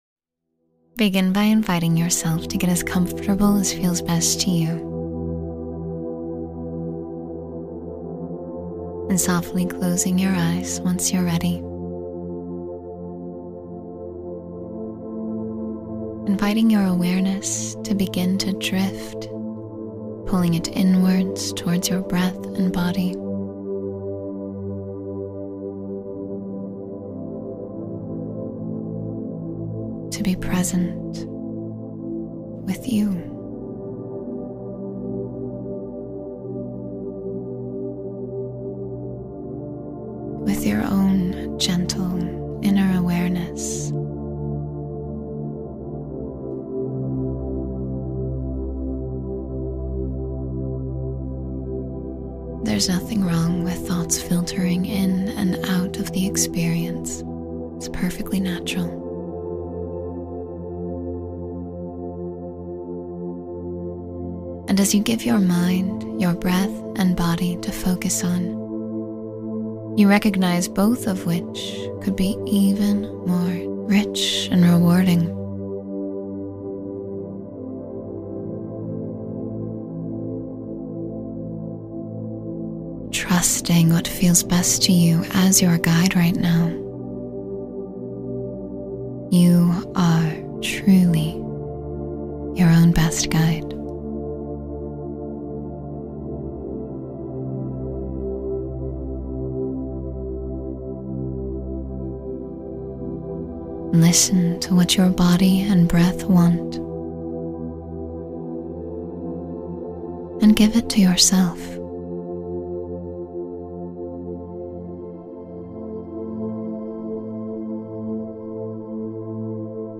Renew Yourself Completely in This Moment — Meditation for Full Rejuvenation